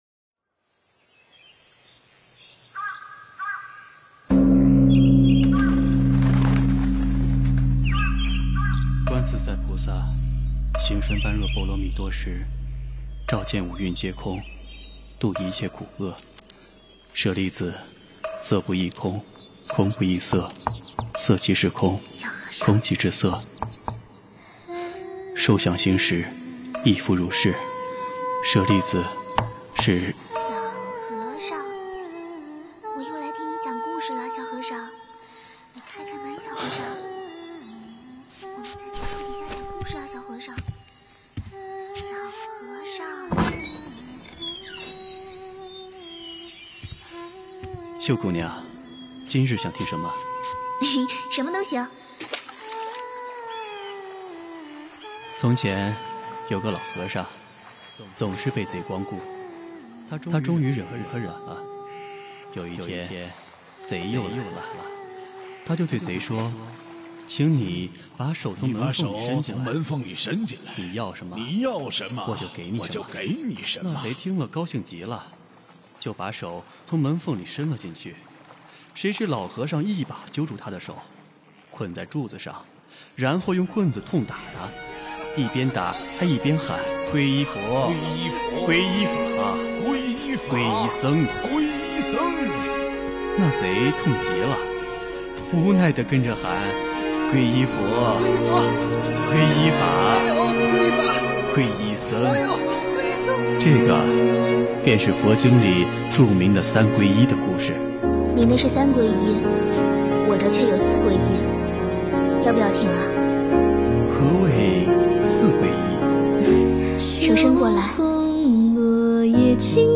佛音 诵经 佛教音乐 返回列表 上一篇： 那一年、那一世 下一篇： 问佛 相关文章 捉真性 捉真性--佛教音乐...